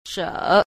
4. 舍 – shě – xả, xá